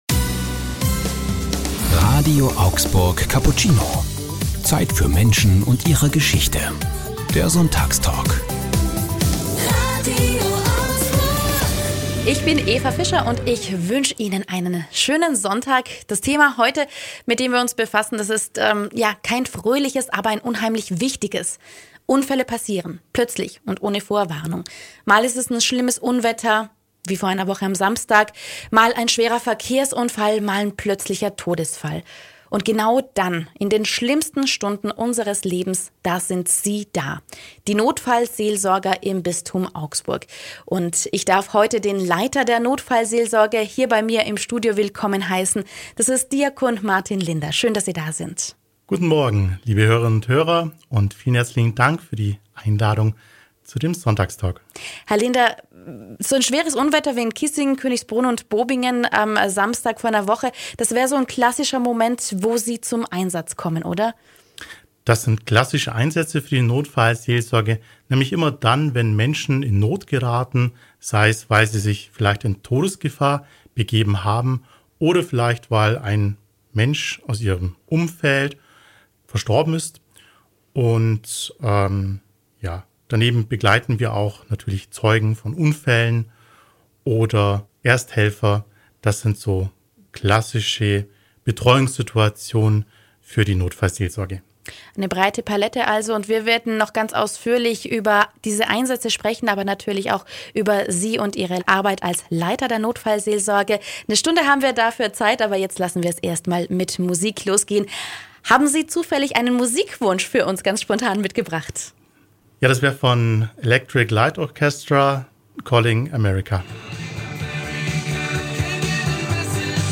Für uns einmal um die ganze Welt: Ein Notfallseelsorger im Sonntagstalk ~ RADIO AUGSBURG Cappuccino Podcast